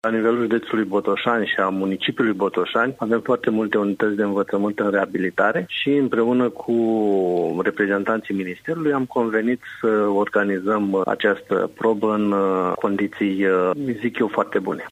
Șeful Inspectoratului Școlar Județean Botoșani, Bogdan Suruciuc, susține că Olimpiada pentru elevii de gimnaziu are loc în condiții foarte bune, iar sala de evenimente a fost aleasă ca spațiu de desfășurare a concursului, deoarece multe școli din municipiu și județ se află în reabilitare: ”La nivelul județului Botoșani și a municipiului Botoșani avem foarte multe unități de învățământ în reabilitare și împreună cu reprezentanții ministerului am convenit să organizăm această probă în condiții, zic eu, foarte bune.”